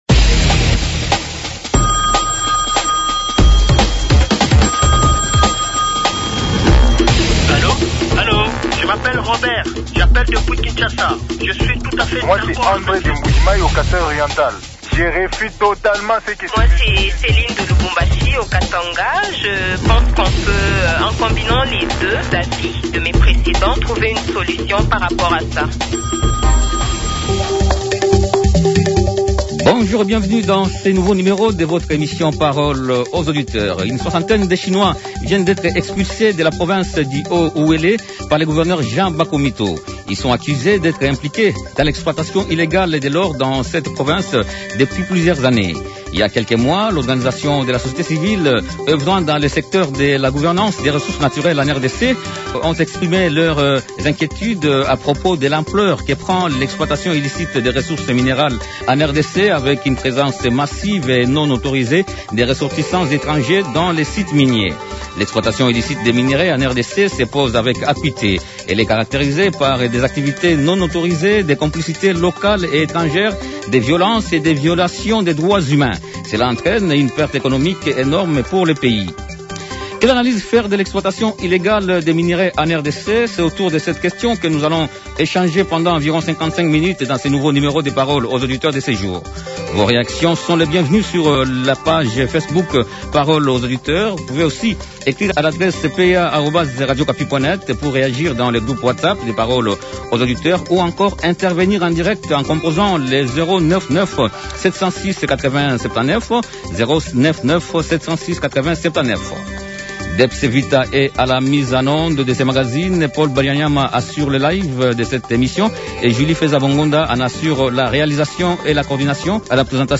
Les auditeurs ont échangé avec Bakomito G Jean, Gouverneur du Haut-Uélé